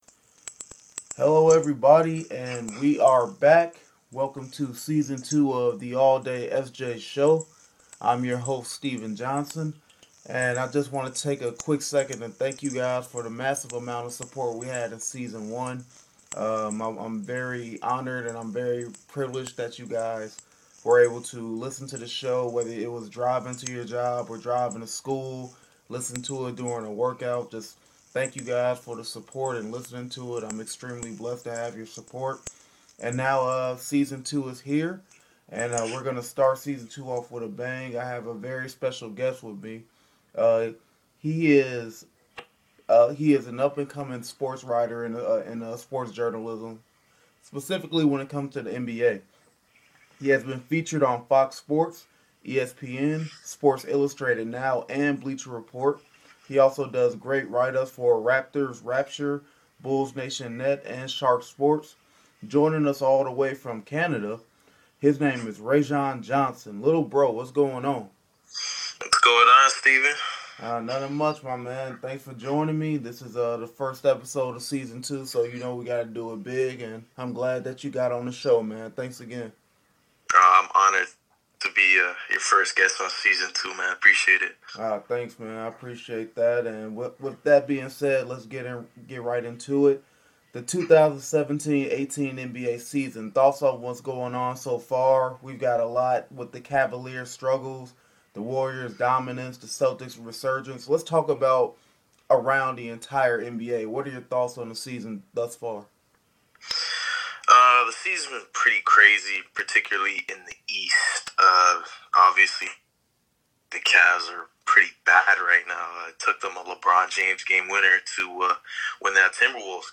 We discuss our thoughts on the 2017-18 season so far, what the future holds for the Chicago Bulls , if this the season the Toronto Raptors finally break through and our predictions for the rest of the season. A great discussion between two up and comers in the sports journalism/broadcast game.